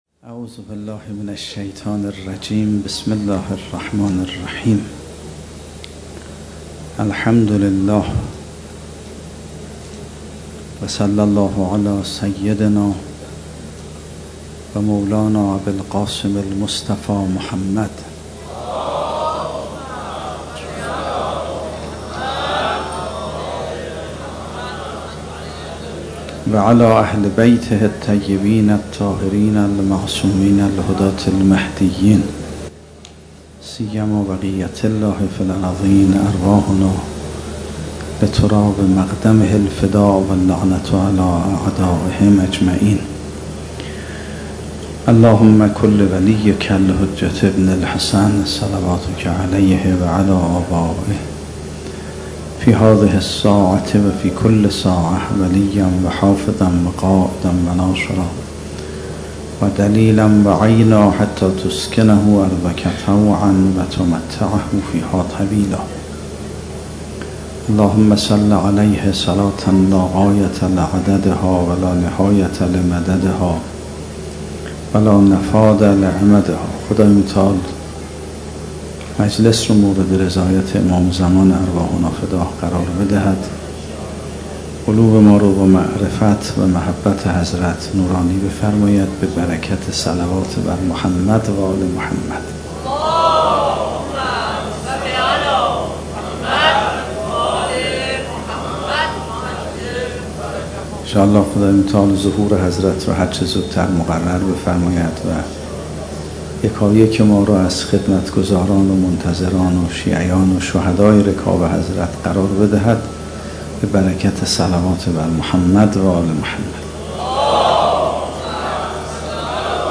سخنرانی: سخنرانی شب اول اربعین آیت الله میرباقری